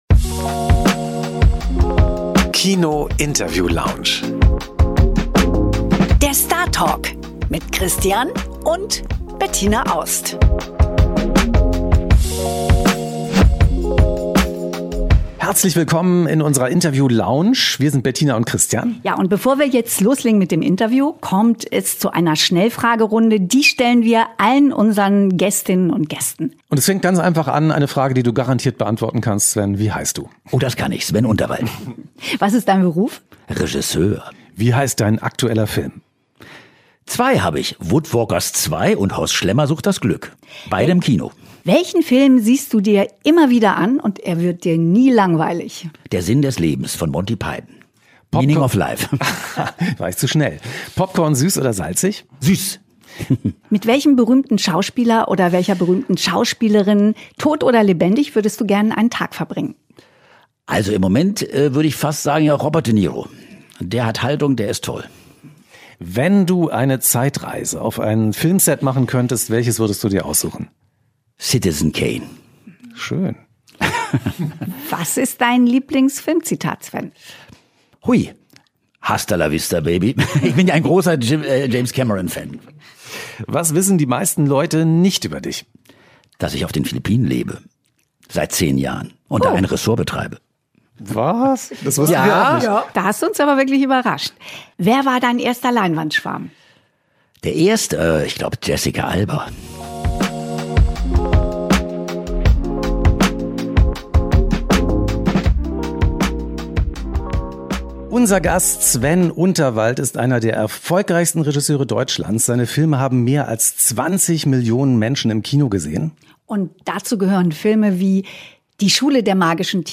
Im Interview sprechen wir über kreativen Wahnsinn, was man als Strandkorbvermieter übers Filmemachen lernt und am Ende hört Ihr als Special Guest auch noch Horst Schlämmer!